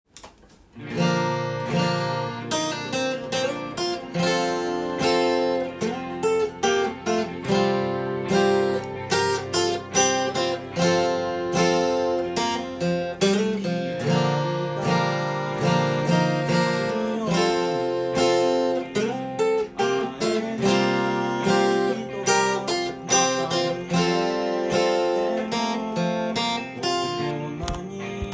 オリジナル音源にあわせて譜面通り弾いてみました
アルペジオなどを入れてみました。